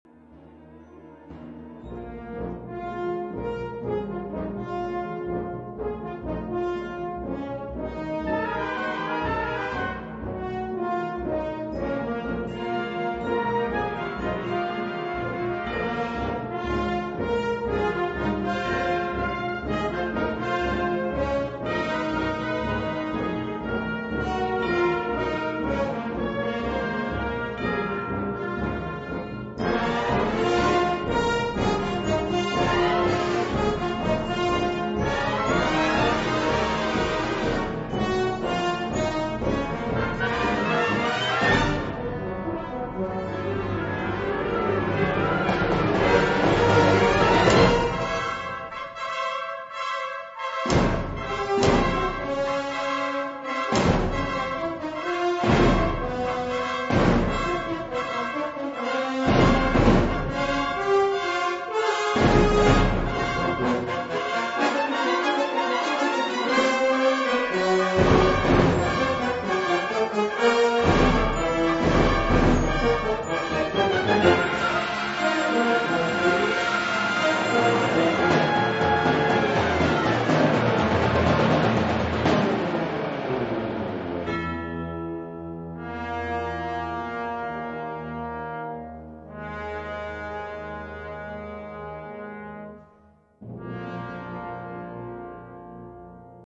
Kategorie Blasorchester/HaFaBra
Unterkategorie Zeitgenössische Bläsermusik (1945-heute)
Besetzung Ha (Blasorchester)